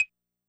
E-mu Drumulator Sample Pack_Wood Block.wav